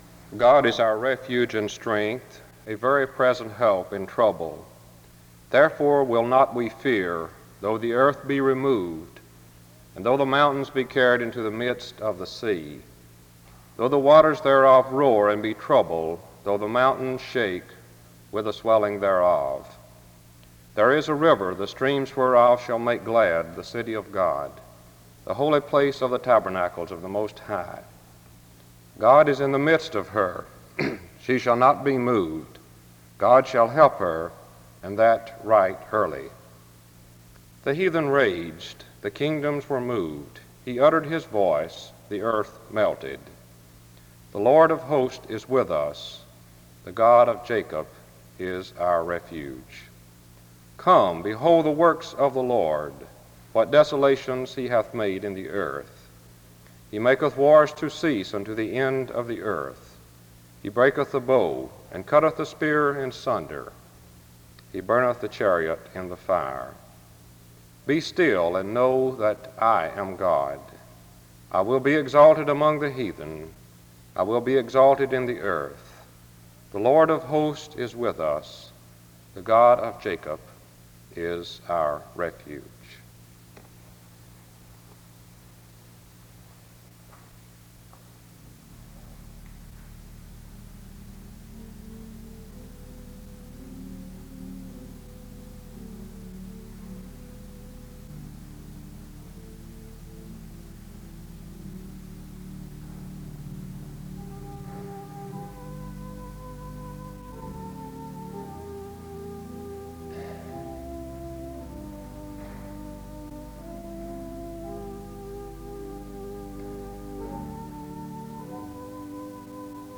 The service opens with an opening scripture reading and music from 0:00-3:37.
Music plays from 23:44-24:06. A closing prayer is offered from 24:07-24:28.